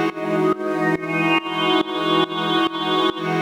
Index of /musicradar/sidechained-samples/140bpm
GnS_Pad-MiscB1:4_140-E.wav